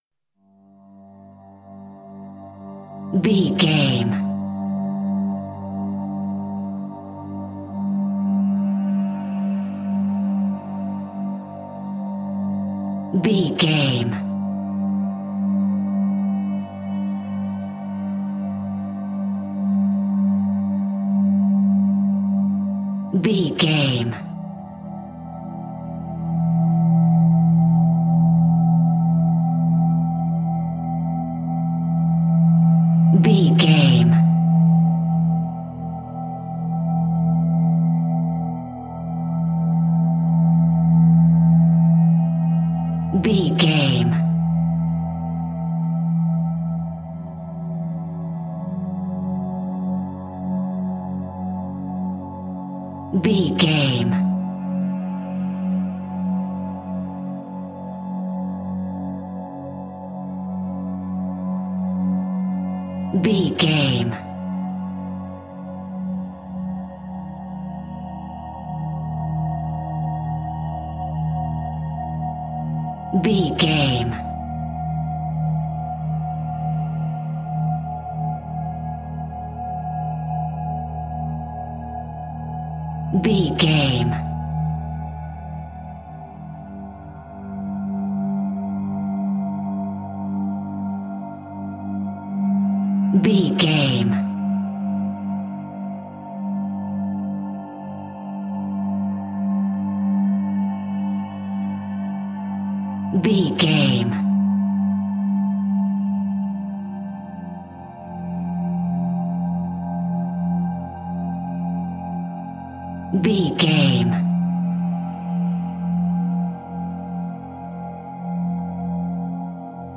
Survival horror
Thriller
Aeolian/Minor
Slow
synthesiser